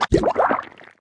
Bubblegun Fire Sound Effect
Download a high-quality bubblegun fire sound effect.
bubblegun-fire-1.mp3